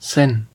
The Seine (/sn, sɛn/ sayn, sen,[1] French: [sɛn]
Fr.Seine.ogg.mp3